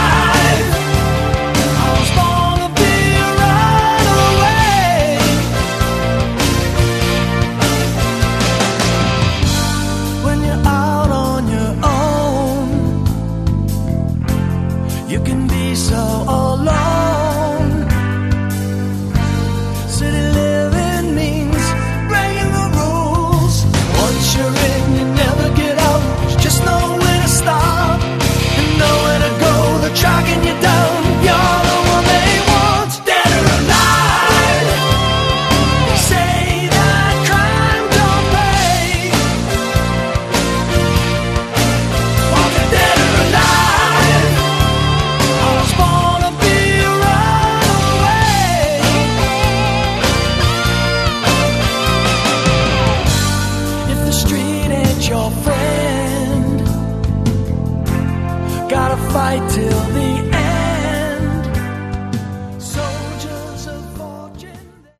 Category: Progish AOR